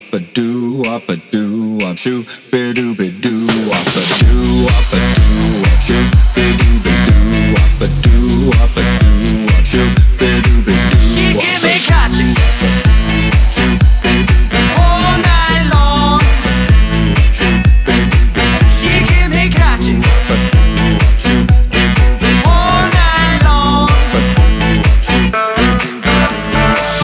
• Качество: 320, Stereo
мужской вокал
dance
Electronic